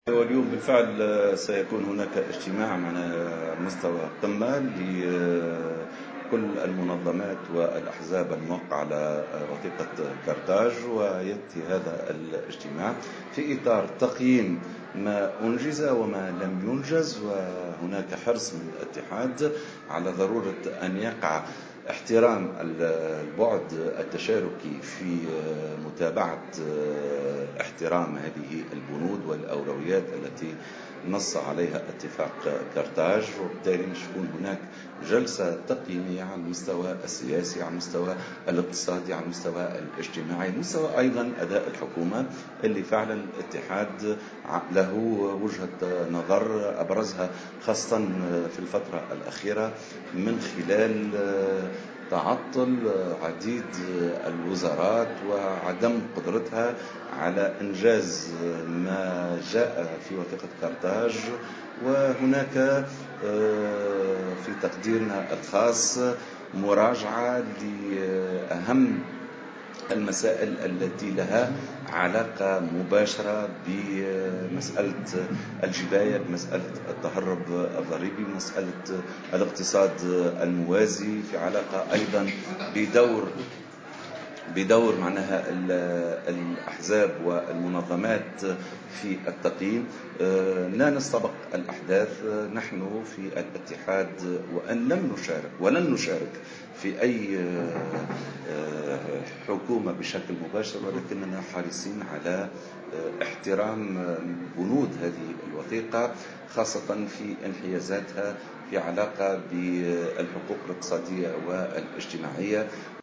تصريح لـ"الجوهرة أف أم"